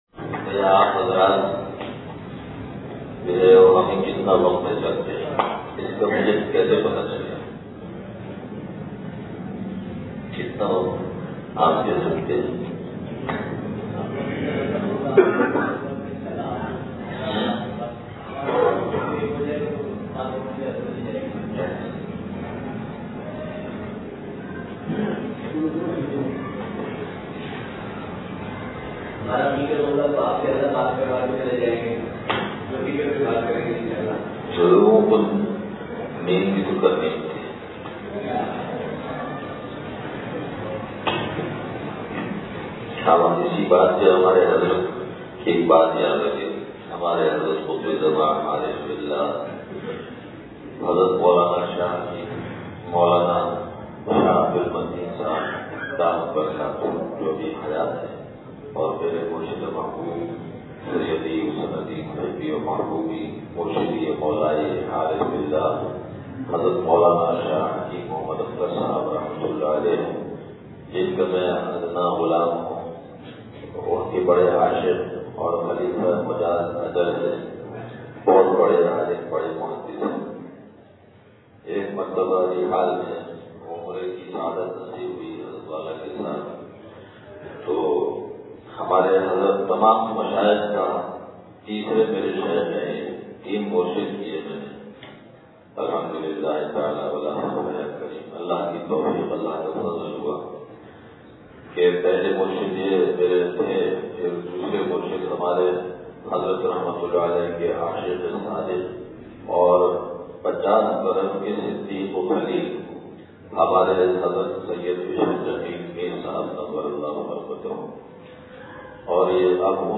مدینہ مسجد منوآباد نواب شاہ سندھ (بعد عشاٰٰء بیان)